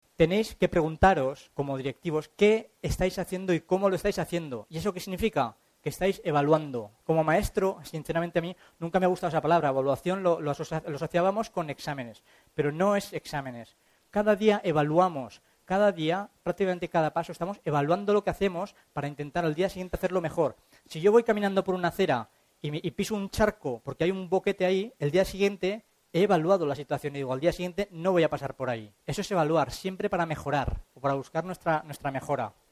Los pasados 30 de junio y 1 de julio se celebró en Madrid reunión del Comité de Coordinación General (CCG) de nuestra Organización.